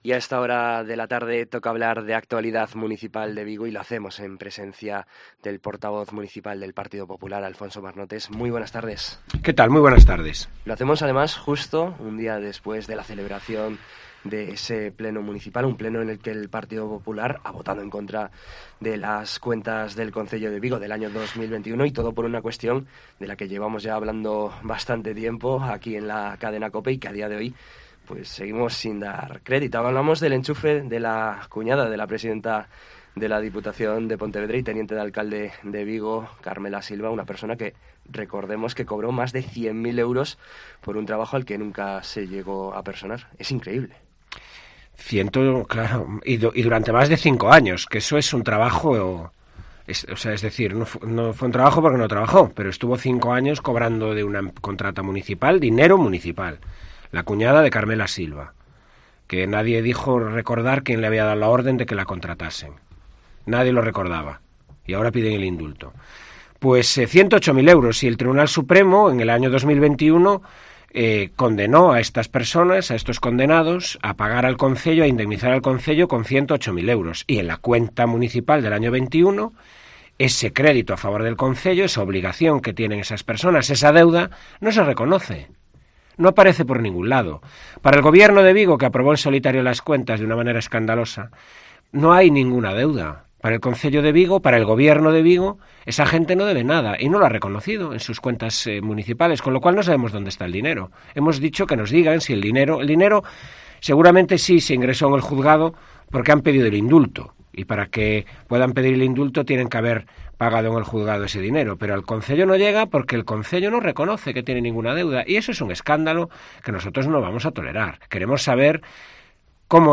AUDIO: En COPE Vigo conocemos la actualidad de Vigo de la mano del portavoz municipal del PP, Alfonso Marnotes